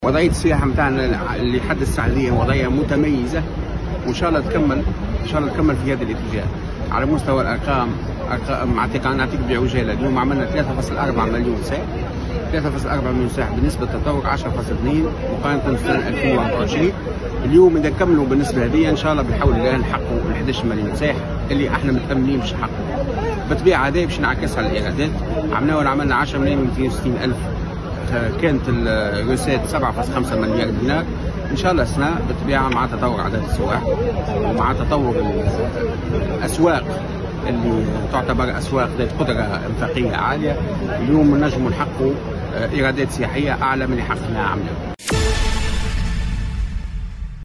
Le responsable a indiqué, dans une déclaration à Diwan FM, que le pays avait accueilli 3,4 millions de touristes depuis le début de l’année, enregistrant une hausse de 10,2 % par rapport à la même période l’an dernier.